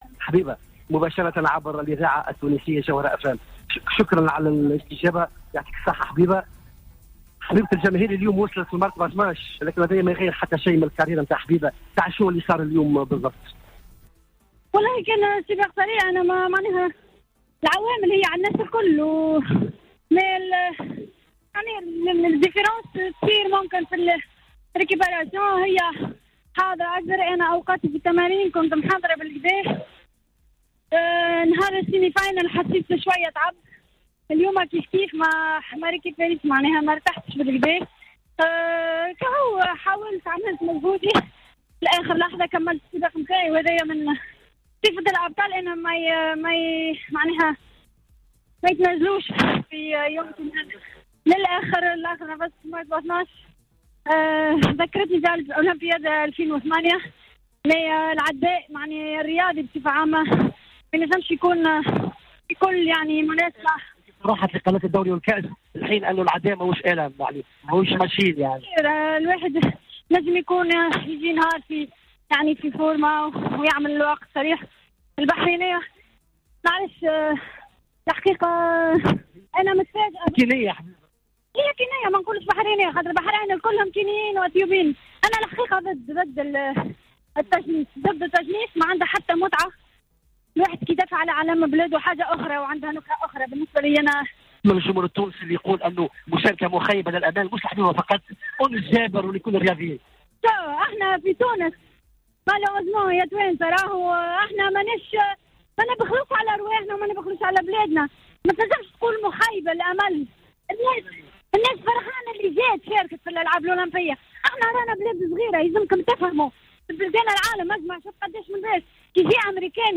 تحدثت العداءة حبيبة الغريبي في تصريح حصري أفردت به راديو جوهرة أف أم و أمنها...